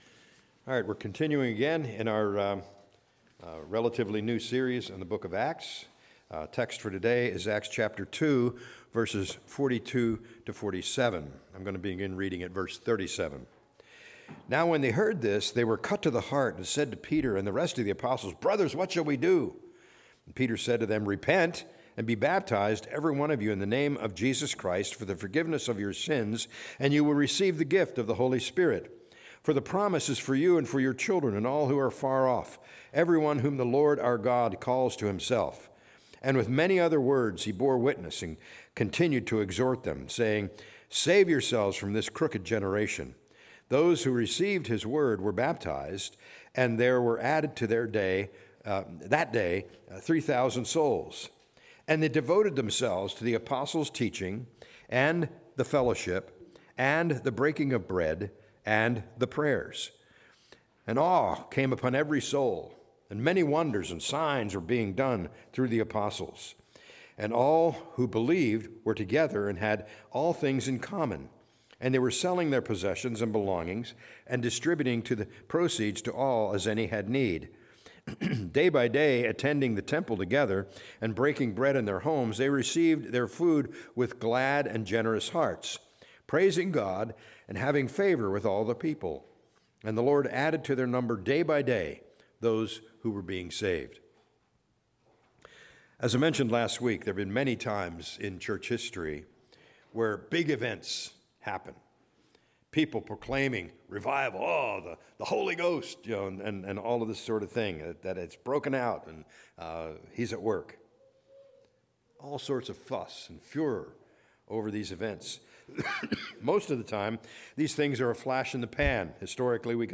A message from the series "Act 2025."